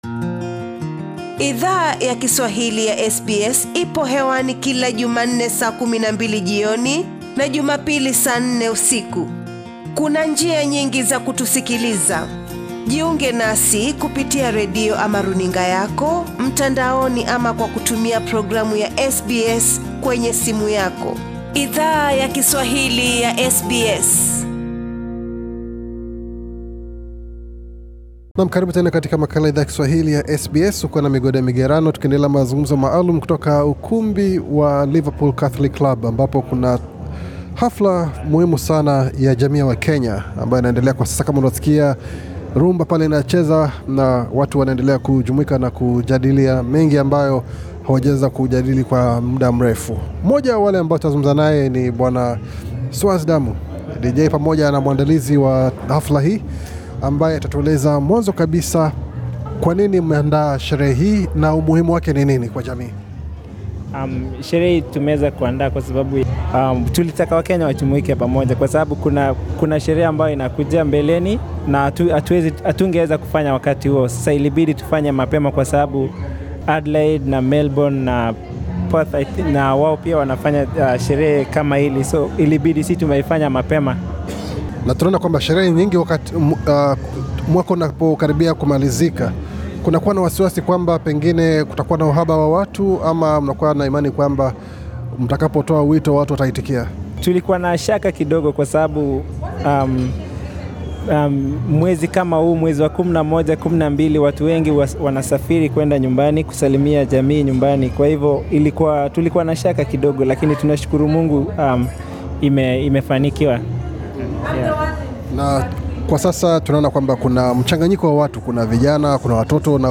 SBS Swahili ili hudhuria tamasha ya "Kenya Night"